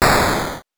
explosion_10.wav